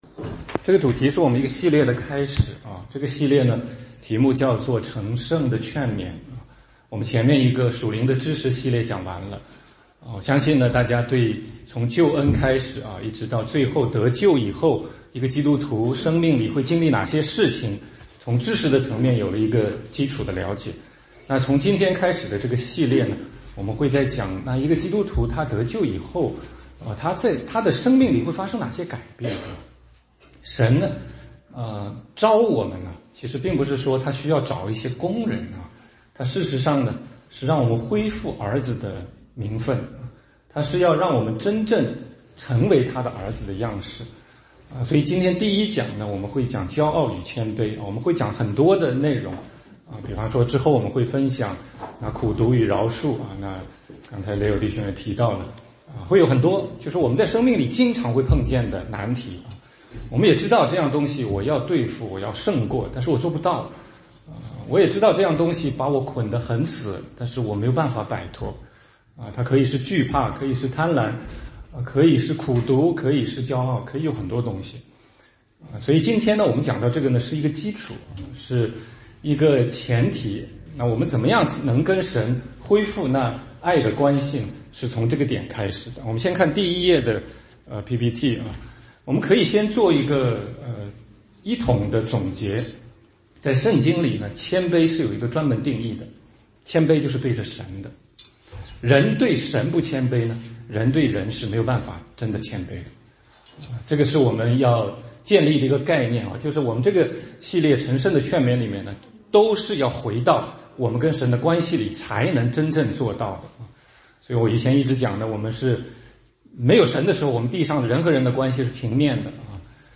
16街讲道录音 - 成圣劝勉系列之一：骄傲和谦卑